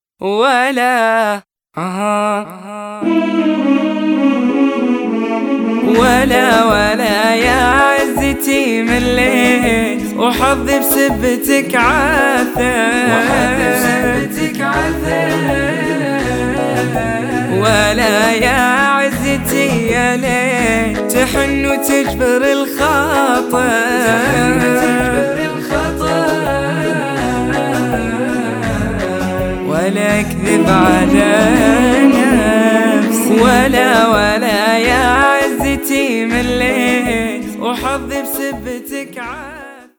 اناشيد